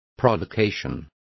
Complete with pronunciation of the translation of provocations.